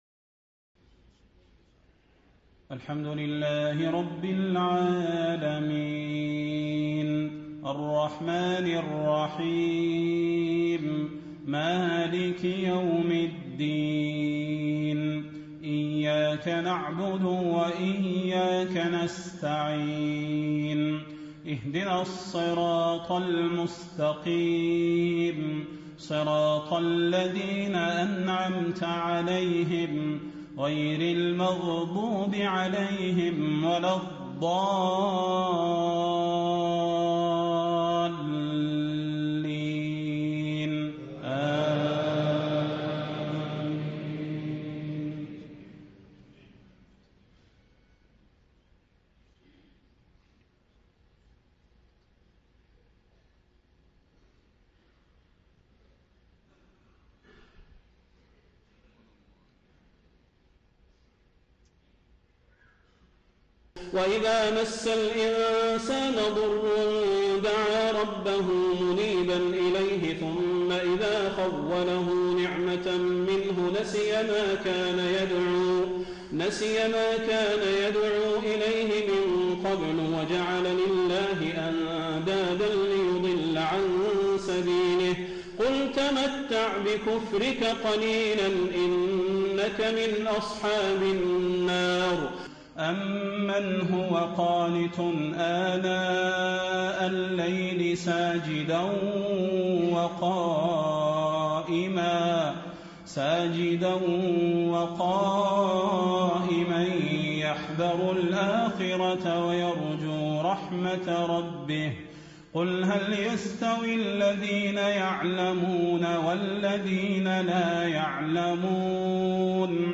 صلاة العشاء 3-3-1436 تلاوة من سورة الزمر .